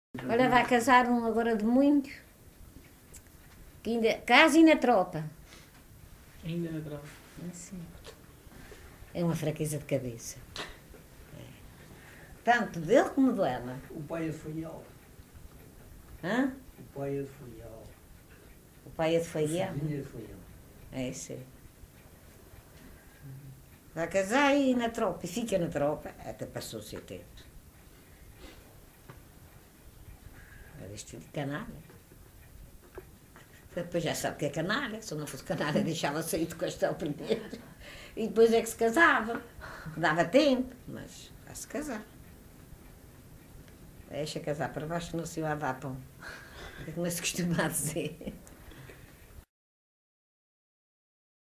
LocalidadeFontinhas (Praia da Vitória, Angra do Heroísmo)